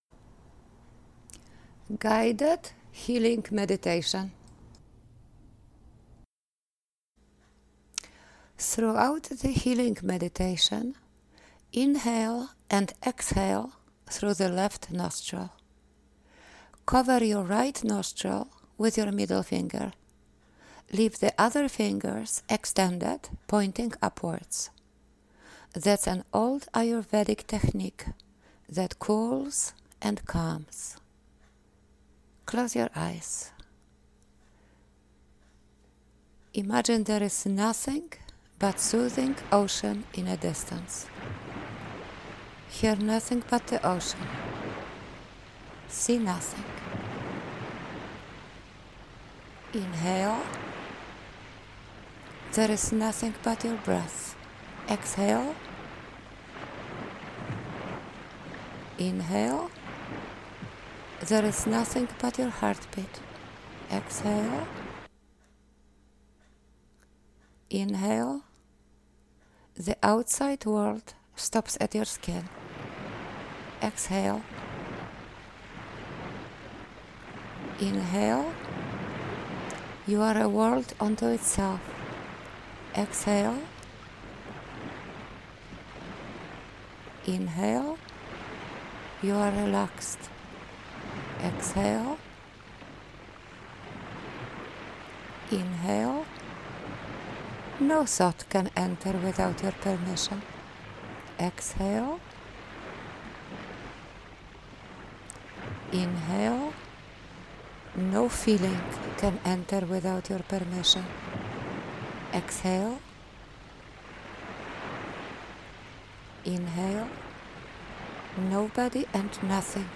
Comforting-Guided-Meditations.mp3